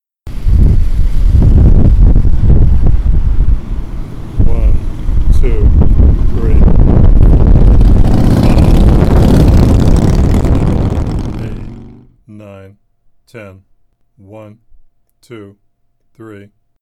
We determine that’s a Harley Motorcycle.
In reality, that was me standing at a very busy intersection. Most of the thumping is unintentional. That was a breeze I hadn’t counted on in my “extensive” planning.